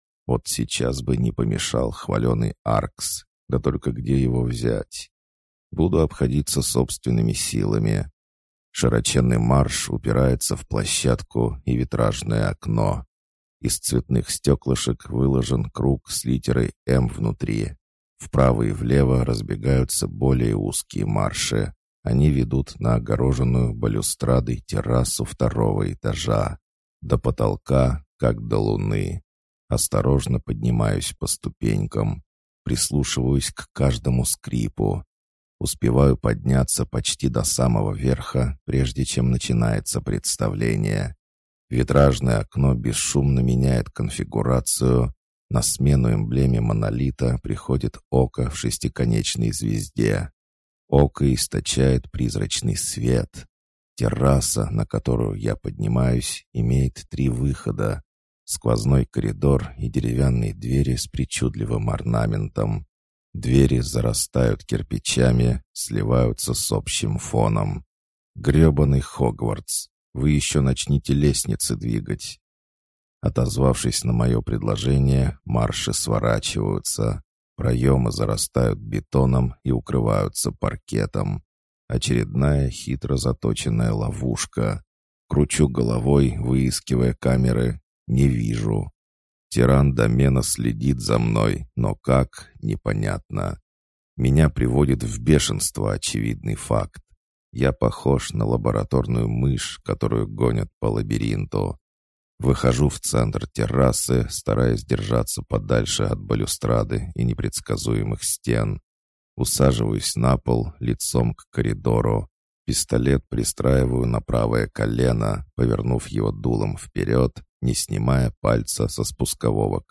Аудиокнига Здесь вам не клан. Книга 3 | Библиотека аудиокниг